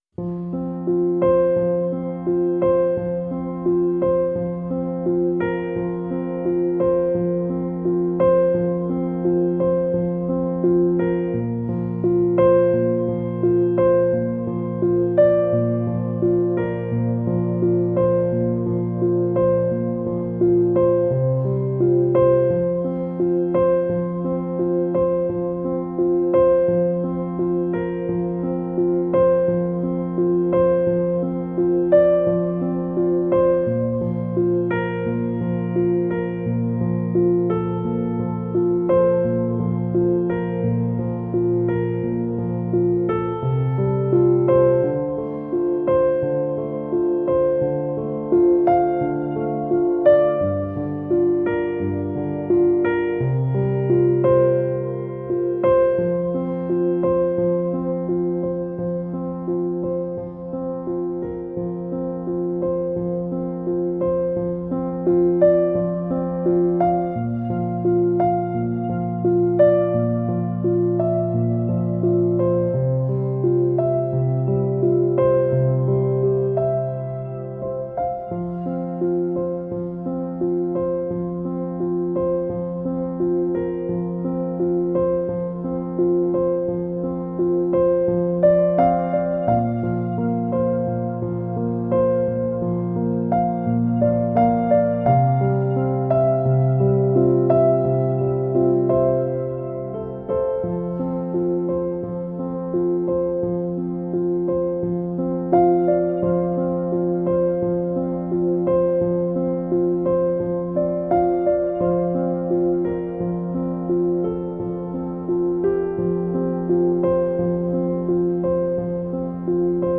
音乐风格；New Age